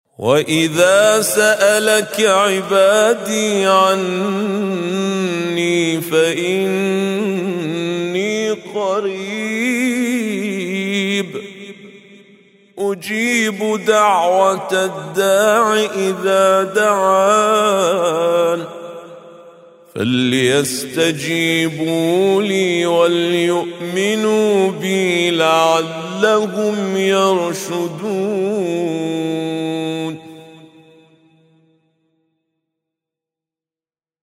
وإذا سألك عبادي عني فإني قريب... - بأسلوب التدوير